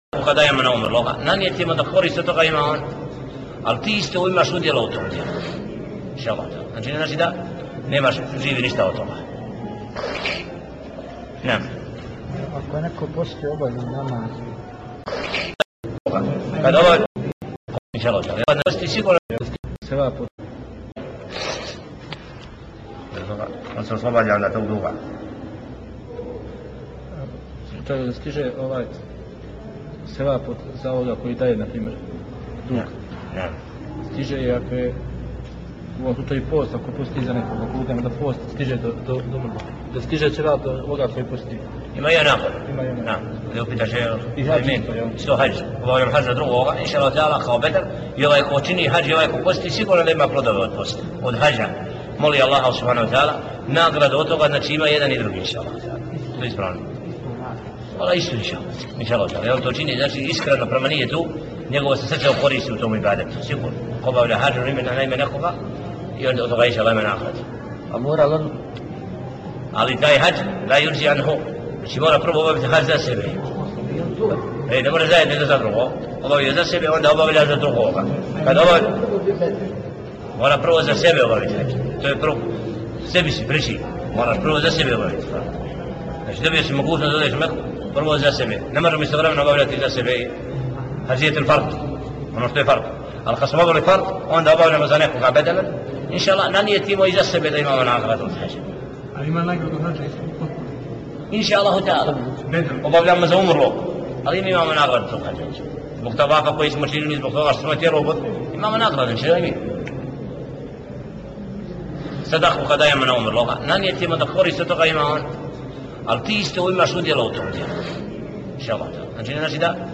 Preuzeto iz video predavanja